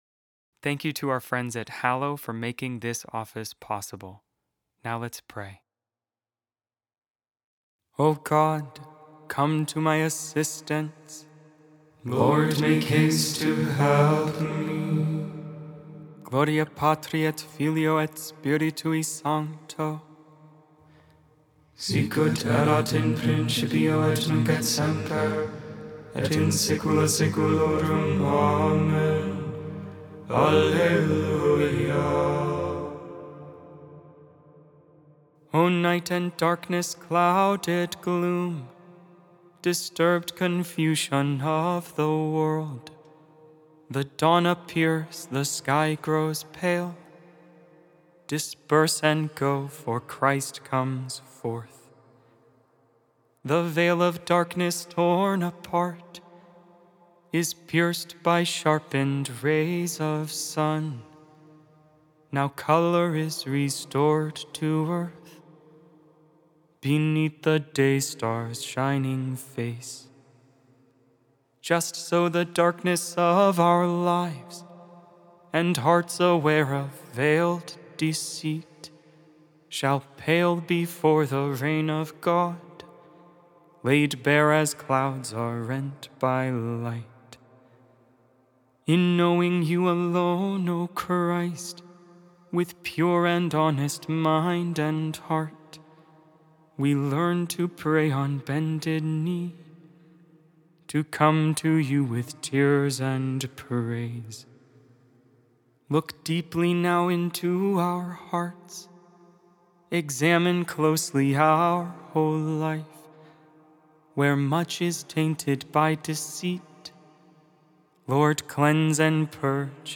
Lauds, Morning Prayer for the 29th Wednesday in Ordinary Time, October 22, 2025. Made without AI. 100% human vocals, 100% real prayer.